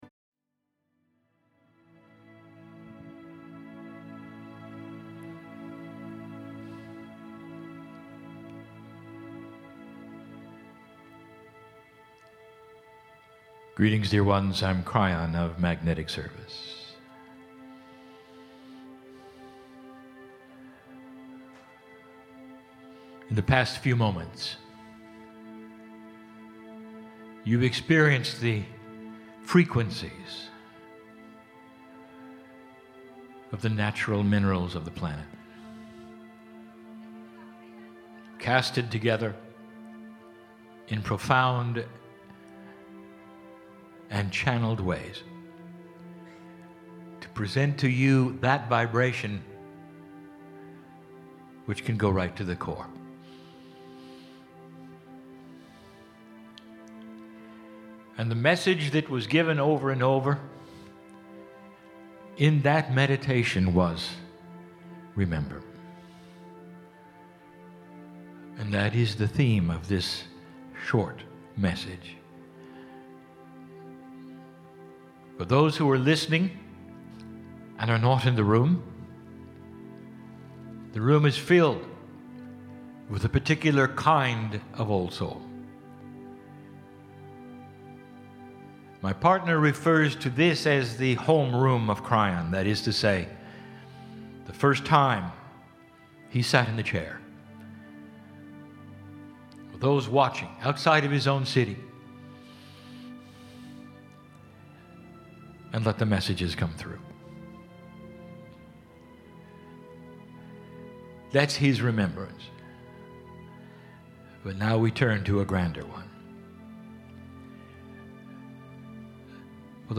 "Mini Channelling"